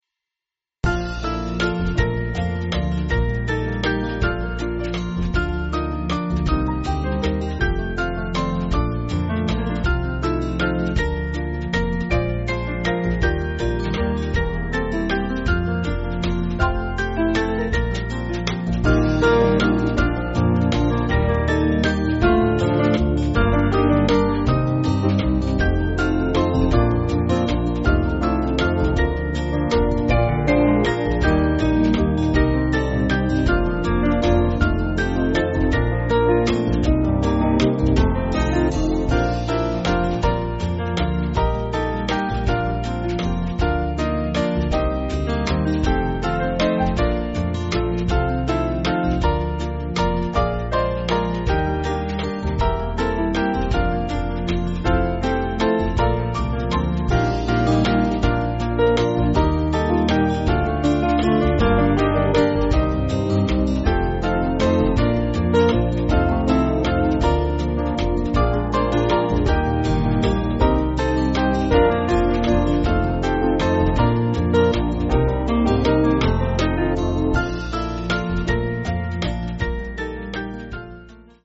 Small Band
(CM)   4/Bb-B